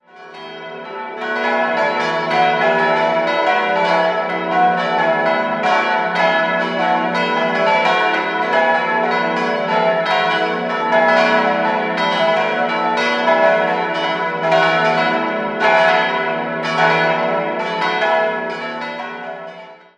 5-stimmiges Geläute: e'-fis'-a'-h'-cis'' Die vier kleineren Glocken wurden 1948 von der Gießerei Otto in Bremen-Hemelingen gegossen, die große komplettierte vier Jahre später das Geläut und wurde bei der Firma Wolfart in Lauingen in Auftrag gegeben.